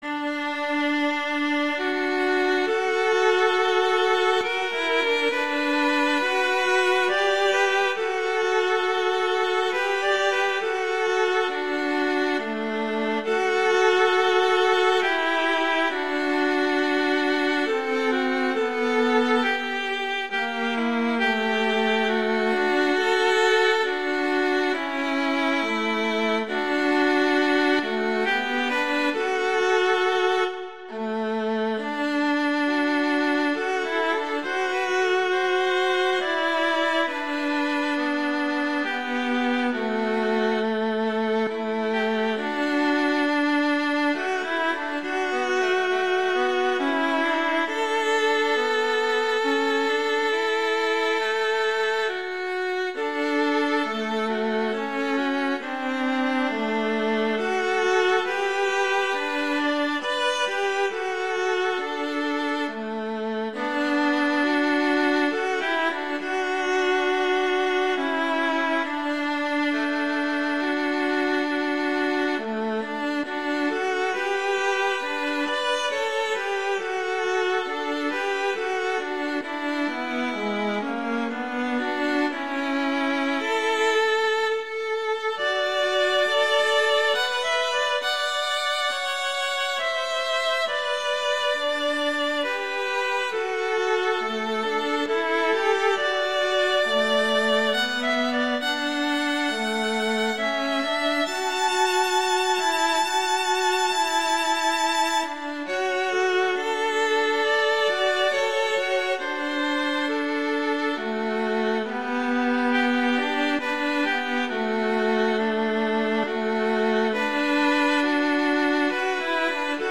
arrangement for violin and viola
traditional, christian, inspirational, hymn
D major
♩=68 BPM (real metronome 69 BPM)